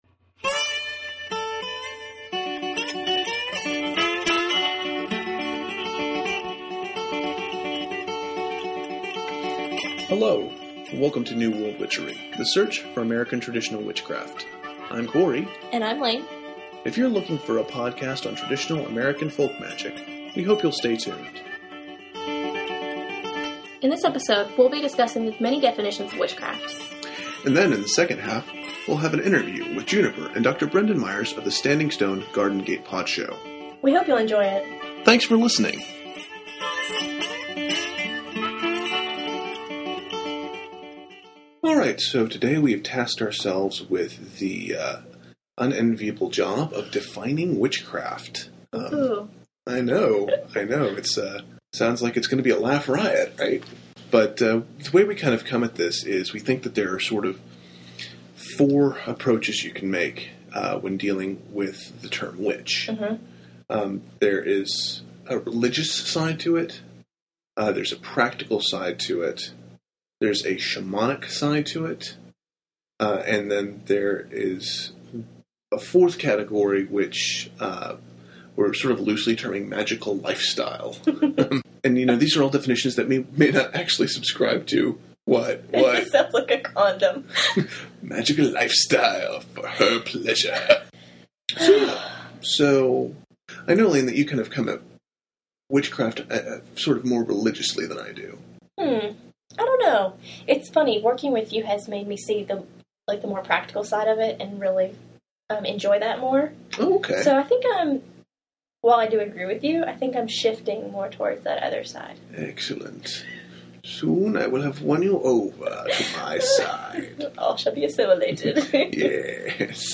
Podcast 4 – Defining “Witches” and an Interview